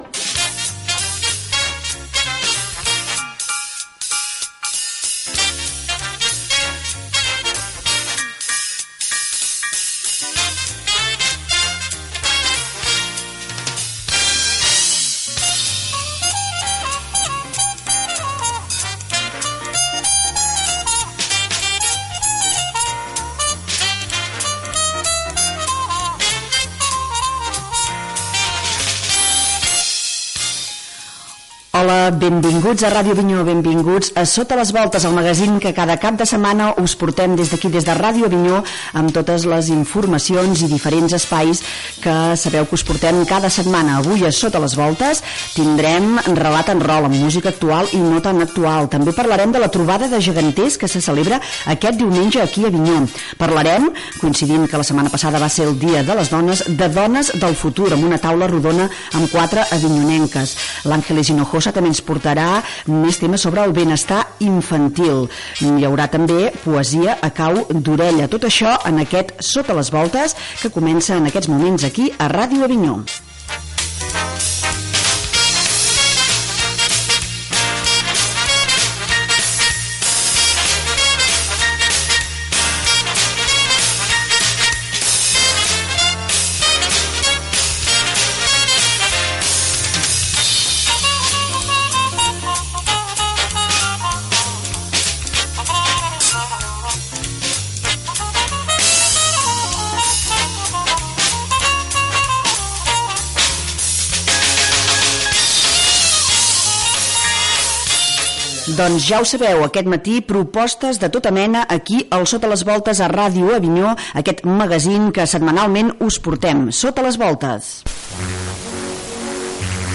Sintonia, sumari del programa, identificació del programa.
Entreteniment